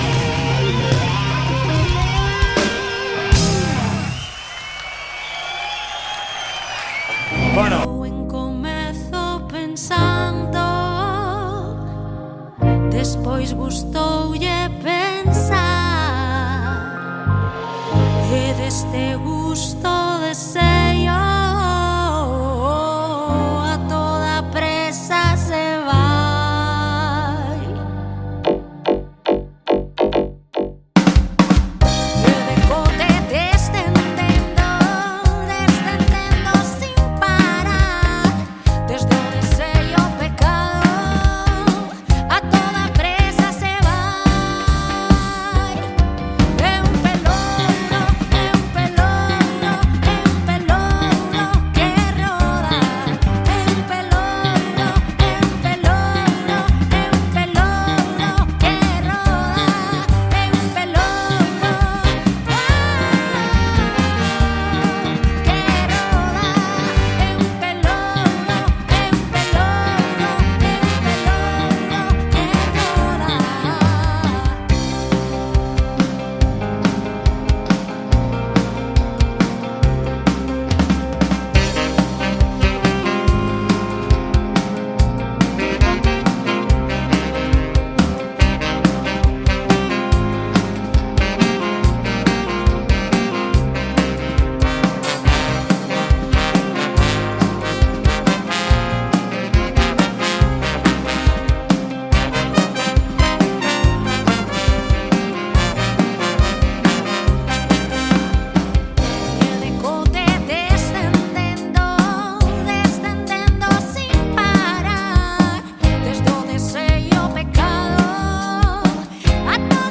La logopedia está en todas partes, desde cómo aprendemos a leer hasta el pitido que escuchamos al salir después de pasar la noche en una discoteca. Entrevistas, comedia, divulgación...